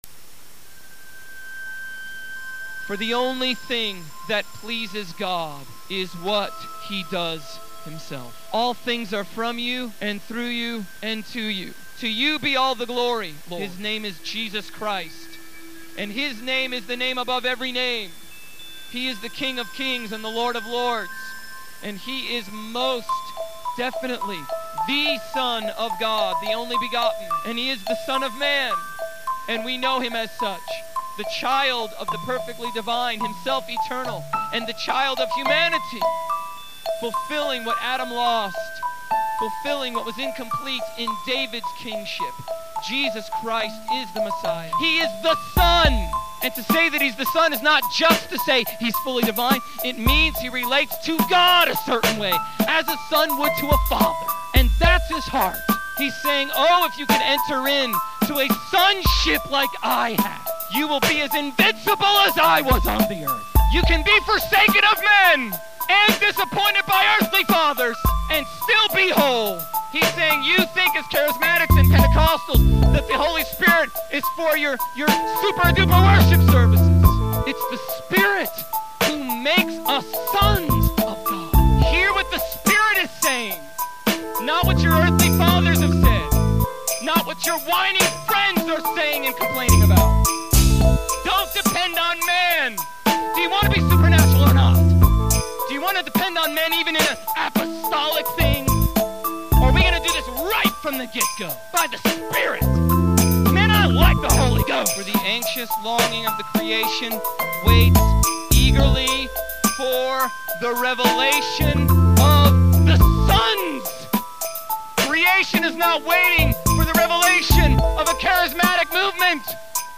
In this sermon, the preacher emphasizes the importance of living with the reality of eternity and everlasting life in mind.